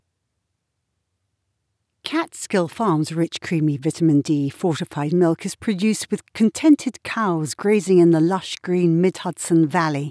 My booth is all acoustic foam or soft fabric lined.
I applied the Audiobook Mastering Suite exactly as written and the result is a technically compliant sound file that passes and sounds exactly like you.